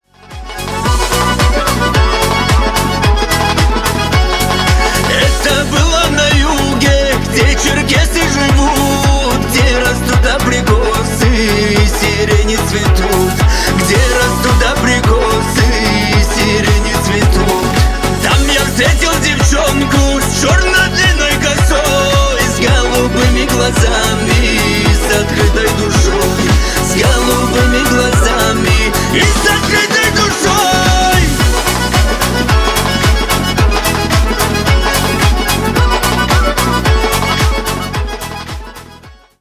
Танцевальные # кавказские